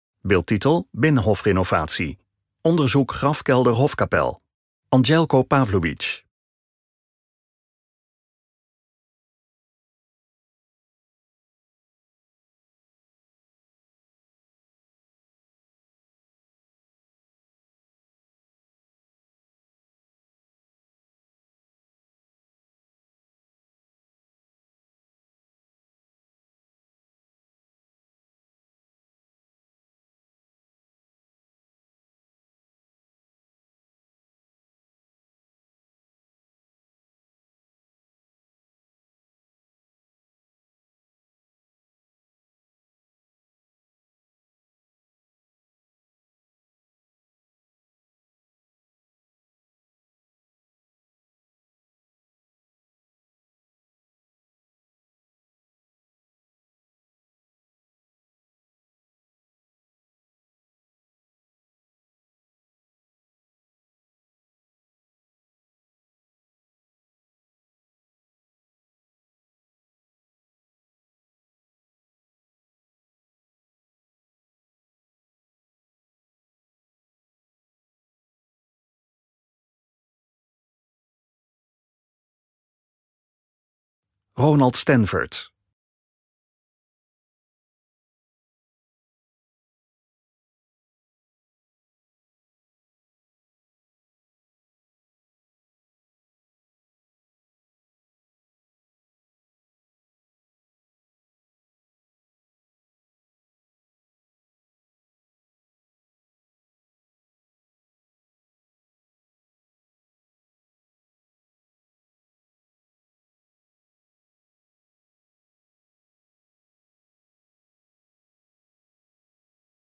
OPGEWEKTE MUZIEK
DE OPGEWEKTE MUZIEK SPEELT VERDER TOT HET EIND VAN DE VIDEO